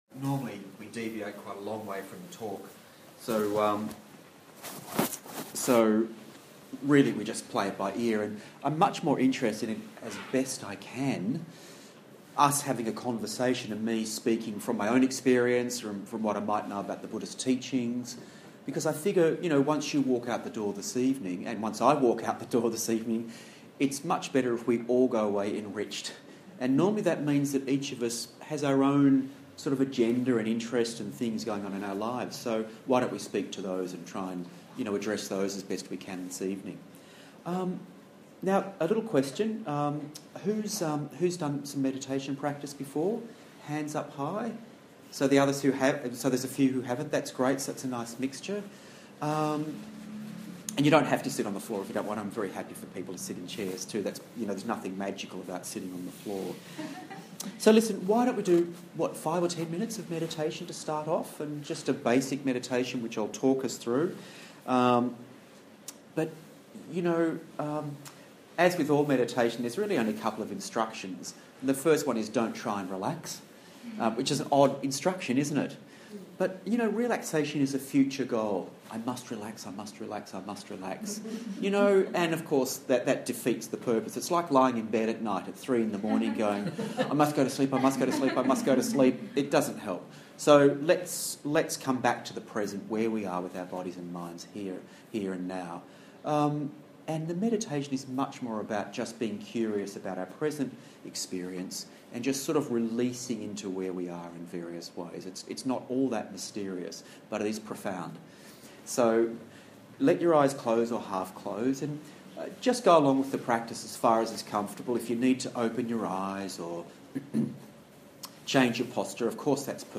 This is a practice session led by Buddhist meditation teacher